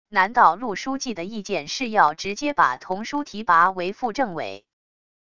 难道陆书记的意见是要直接把佟舒提拔为副政委wav音频生成系统WAV Audio Player